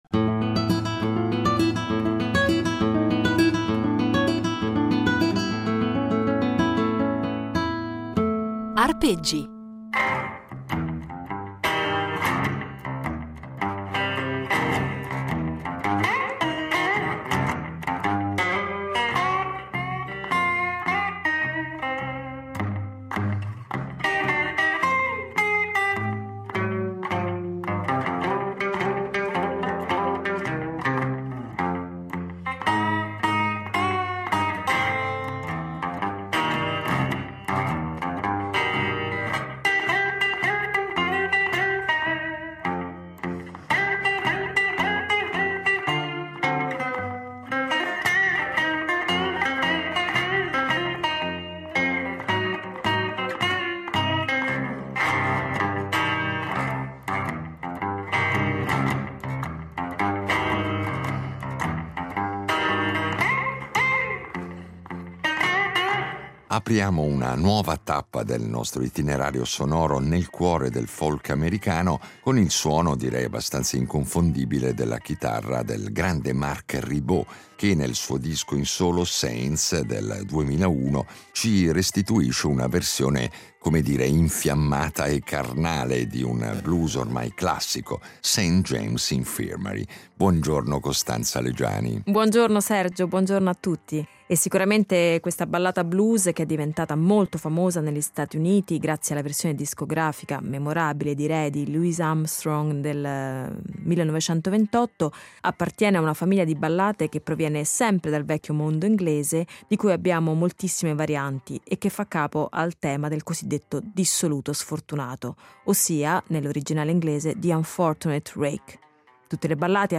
Non solo, però, perché i nostri itinerari sonori saranno impreziositi dalle esecuzioni “live”, e quindi inedite